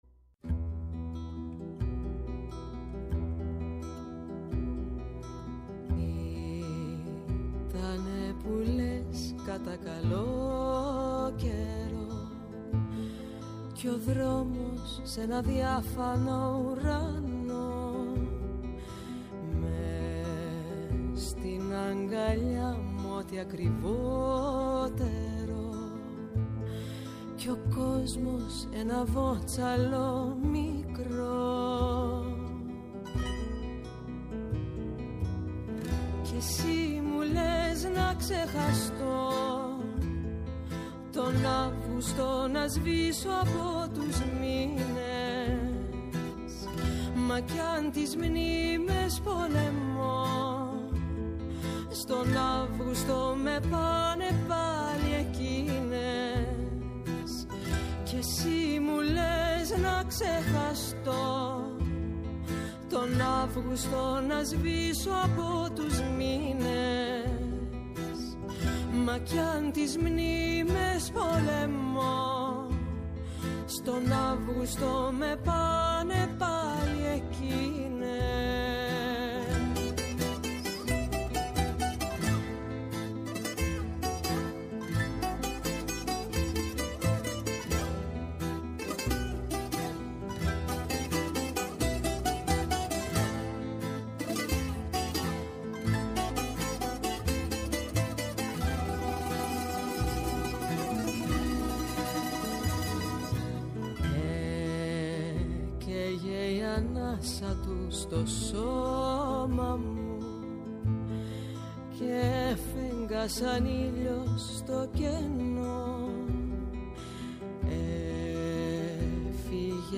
ΔΕΥΤΕΡΟ ΠΡΟΓΡΑΜΜΑ Μουσική Συνεντεύξεις